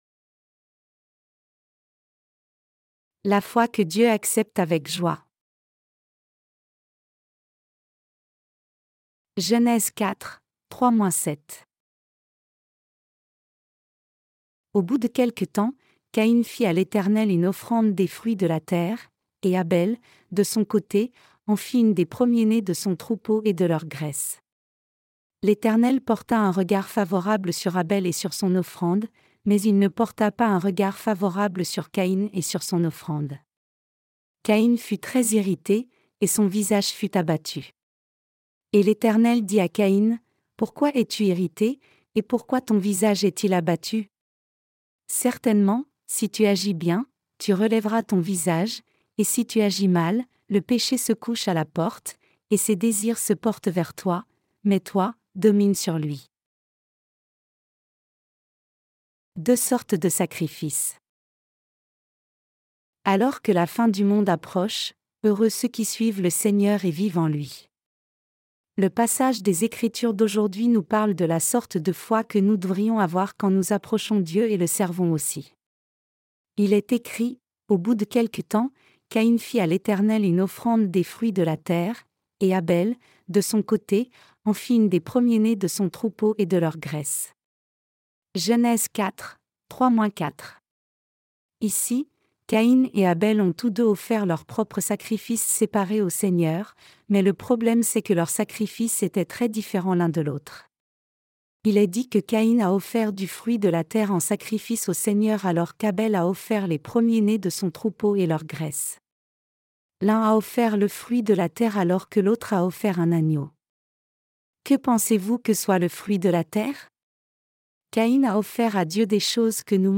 Sermons sur la Genèse (V) - LA DIFFERENCE ENTRE LA FOI D’ABEL ET LA FOI DE CAÏN 3.